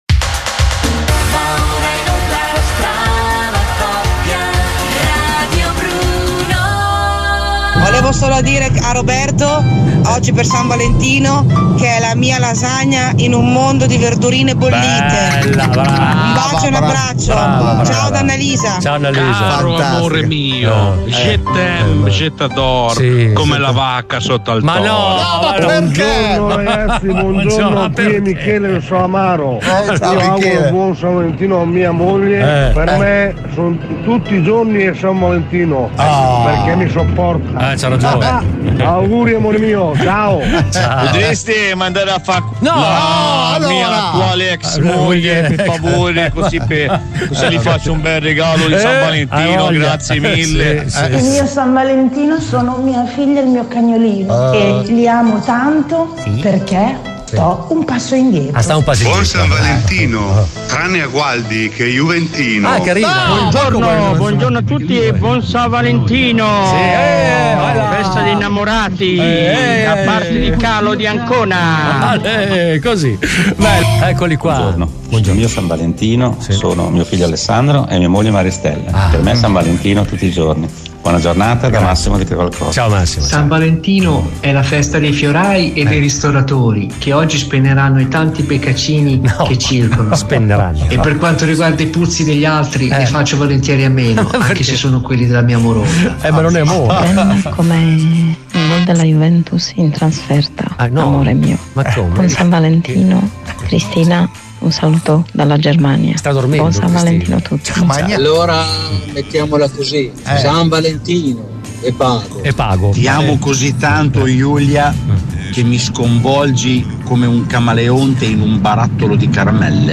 Una puntata dedicata all’ amore: dai messaggi vocali, agli auguri a sorpresa con richiesta di matrimonio. Al gran finale: lui che la conquistò con un mazzo di cipollotti.